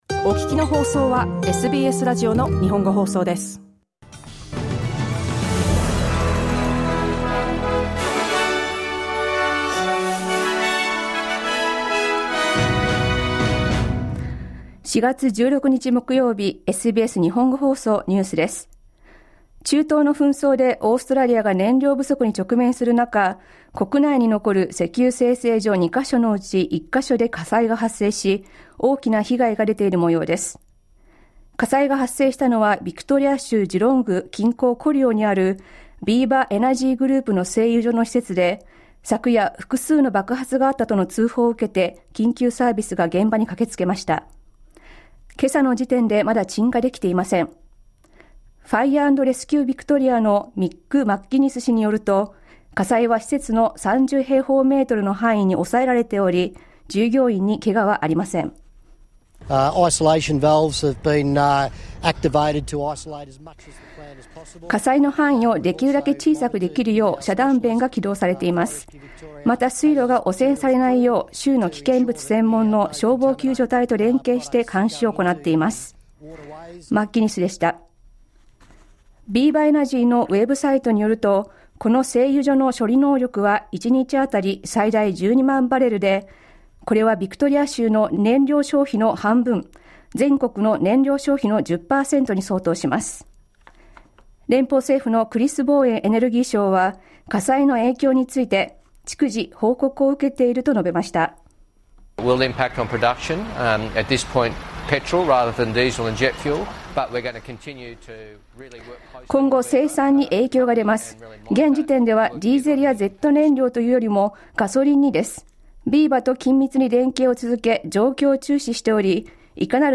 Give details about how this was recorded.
Treasurer Jim Chalmers is in the US today for crucial talks, as the International Monetary Fund delivers a grim forecast of a possible global recession. News from today's live program (1-2pm).